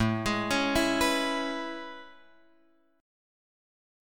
A Major 9th